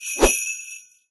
sh_bell_c_4.wav